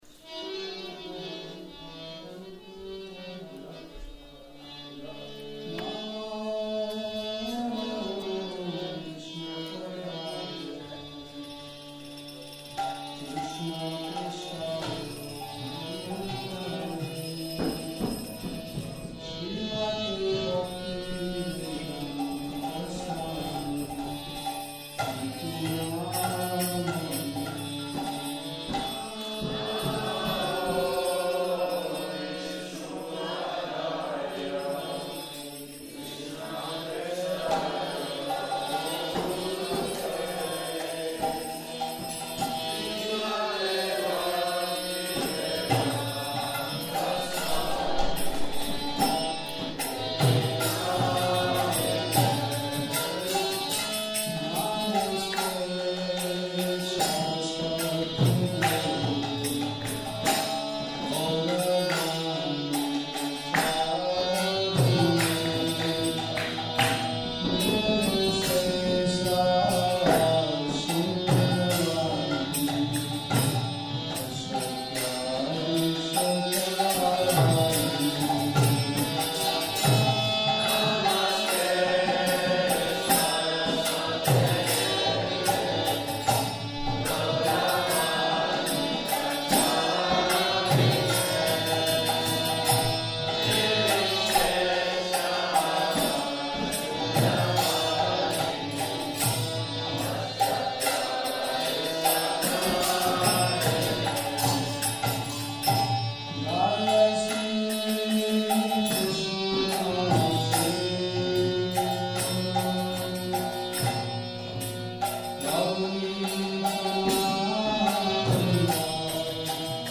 Kírtan